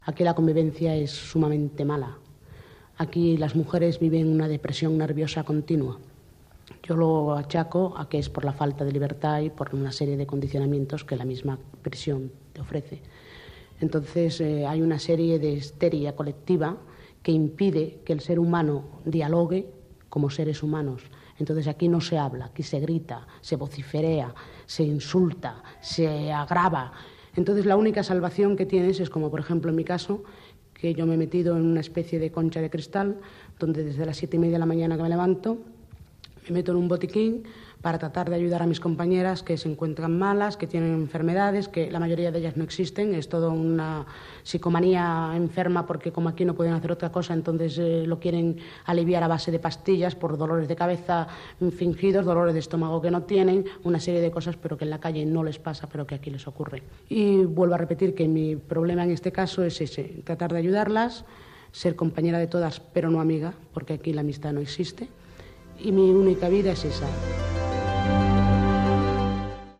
Opinió d'una reclusa de la presó de dones de Yeserías (posteriorment Centre d'Inserció Social Victoria Kent), situada al districte d'Arganzuela de Madrid
Divulgació
Programa presentat per Manuel Torreiglesias.